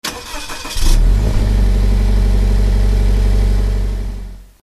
Home gmod sound vehicles tdmcars frs
enginestart.mp3